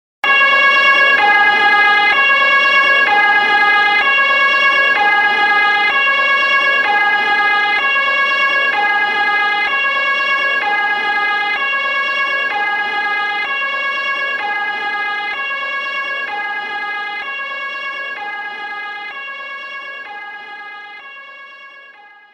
P O L I C E
swedish-police-siren_24861.mp3